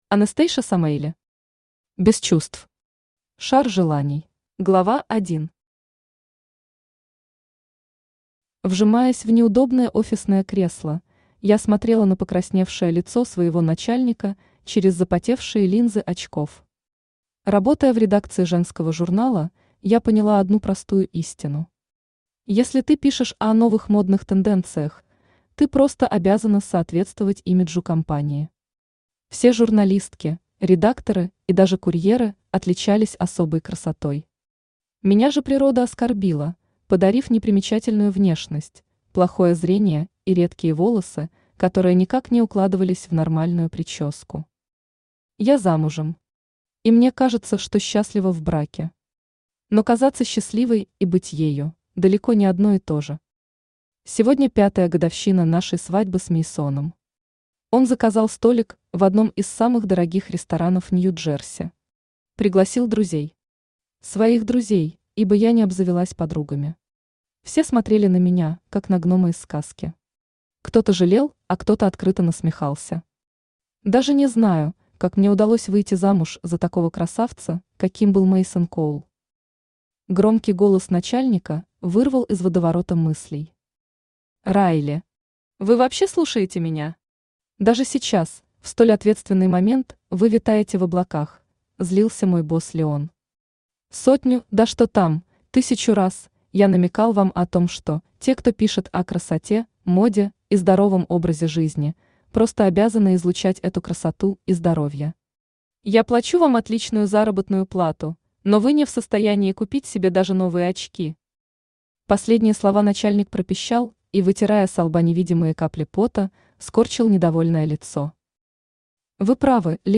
Аудиокнига Без чувств. Шар желаний | Библиотека аудиокниг
Шар желаний Автор Anastasia Avi Samaeli Читает аудиокнигу Авточтец ЛитРес.